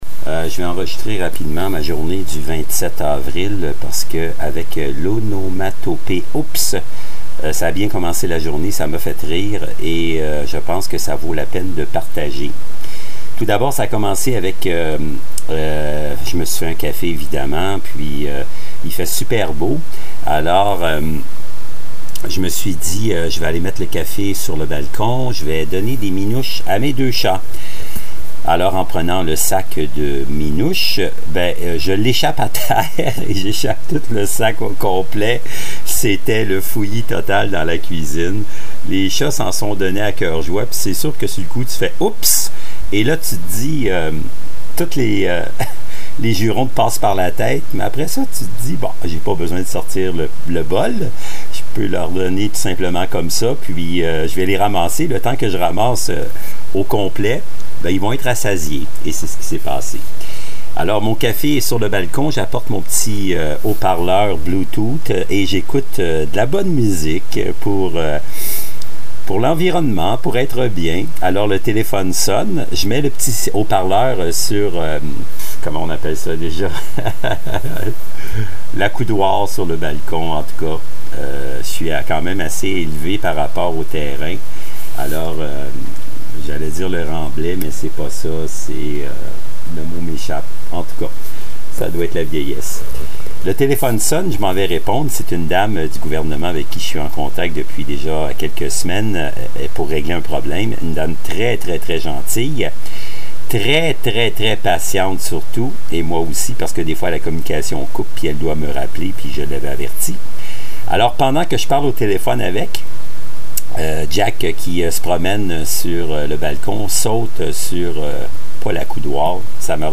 Qualité sonore vraiment pas top 😉